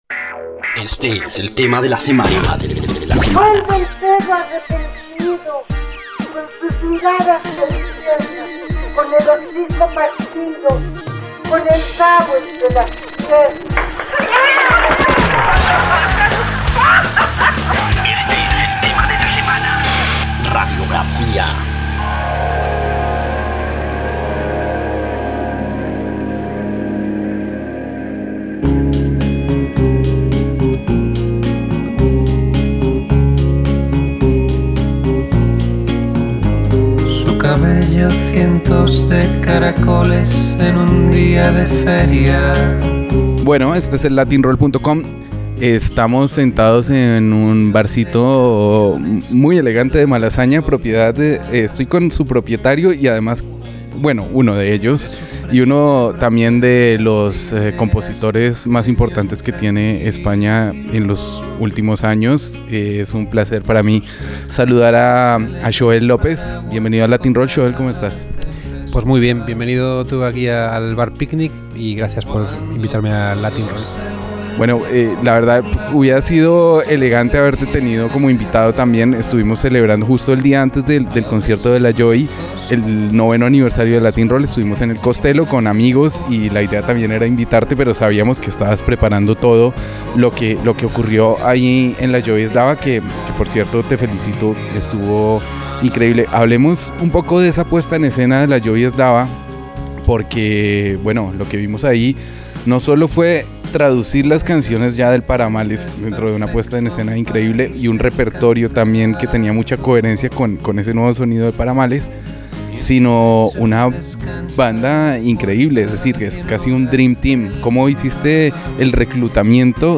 Latin-Roll - Entrevistas Xoel Lopez Reproducir episodio Pausar episodio Mute/Unmute Episode Rebobinar 10 segundos 1x Fast Forward 30 seconds 00:00 / Suscribir Compartir Feed RSS Compartir Enlace Incrustar